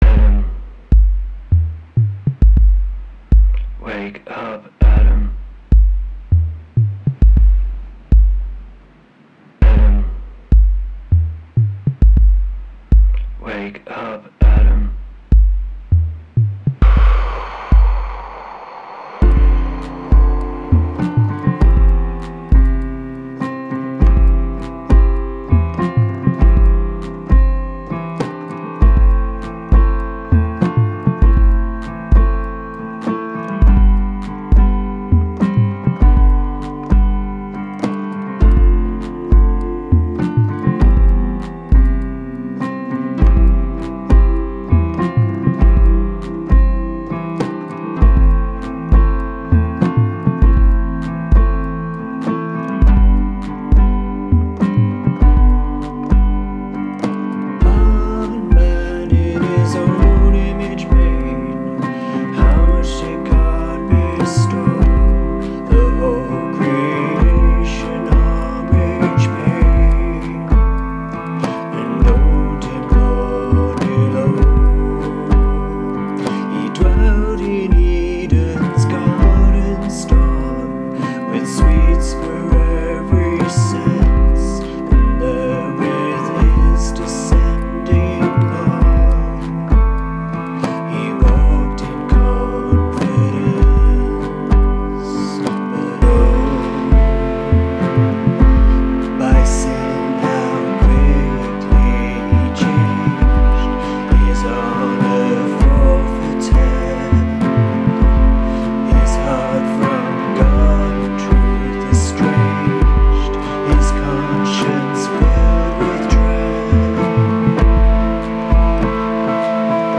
It is a powerful retelling of Adam’s fall, told from the perspective of the Gospel. You’ll have to forgive the technical shoddiness of the recording, but I hope that it can help to open new audiences up to the beauty of Newton’s words.
adams-hymn.m4a